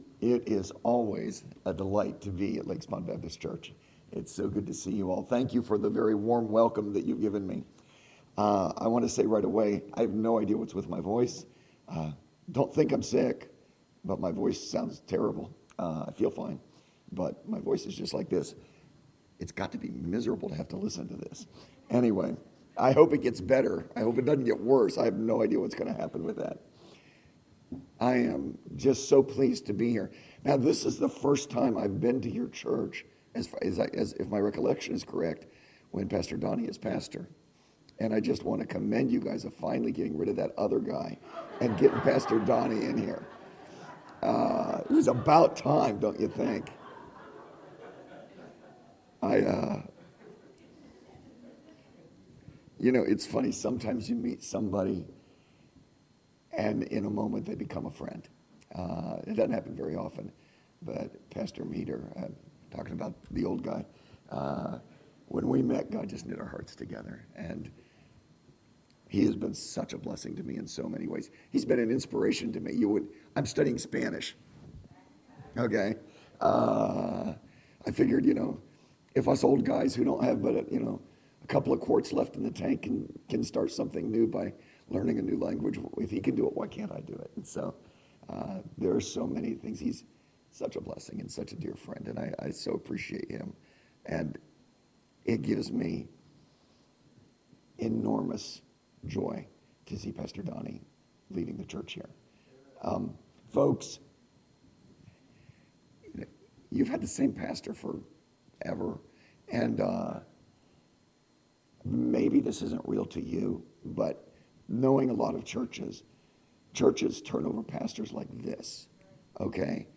Series: Sunday AM